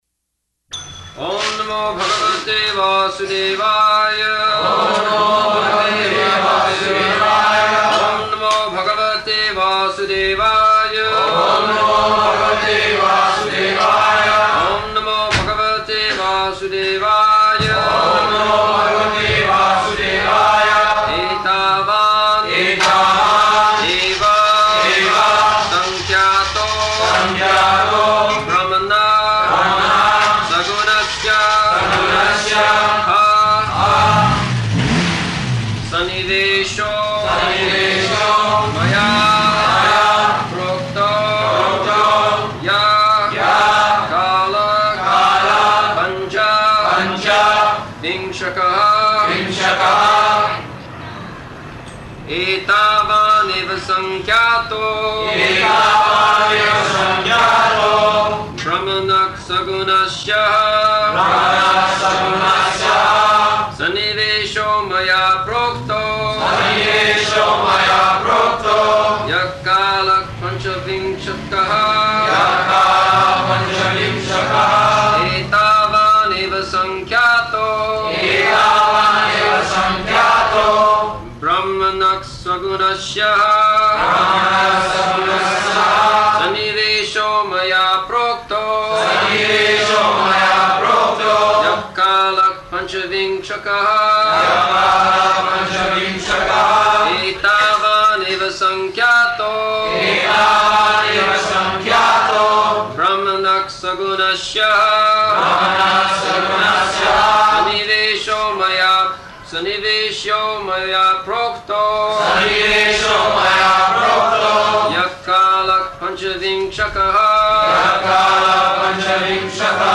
December 24th 1974 Location: Bombay Audio file
[devotees repeat] [leads chanting of verse, etc.] etāvān eva saṅkhyāto brahmaṇaḥ saguṇasya ha sanniveśo mayā prokto yaḥ kālaḥ pañca-viṁśakaḥ [ SB 3.26.15 ] [break] Prabhupāda: This chanting is chanting of the veda-mantra.